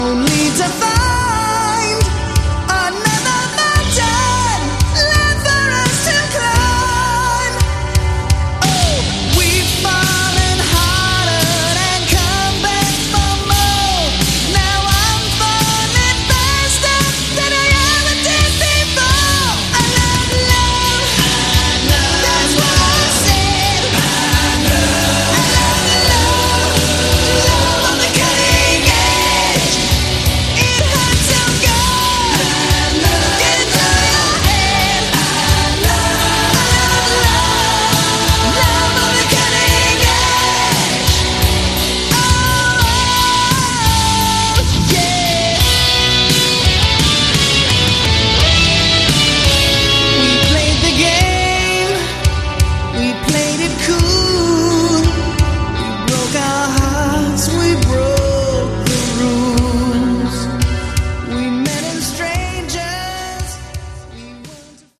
Category: Hard Rock
power ballads